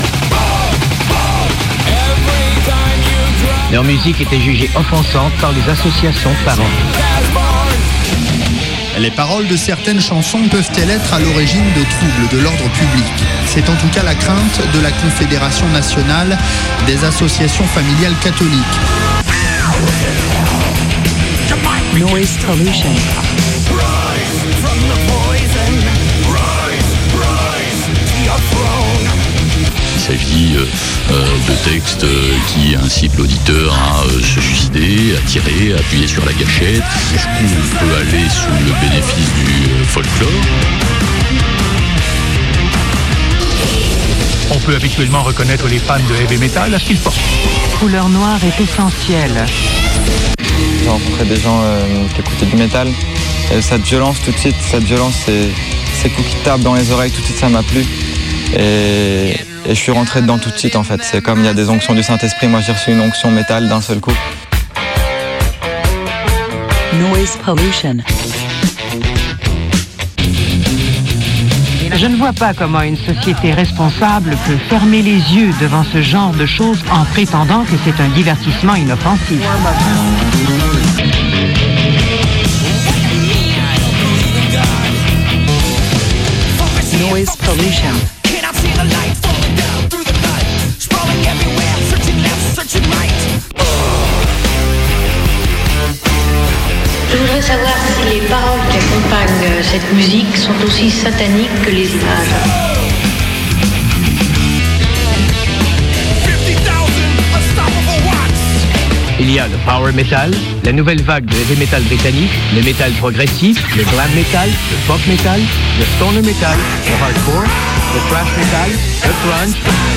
Noise Pollution - Une émission avec du metal dedans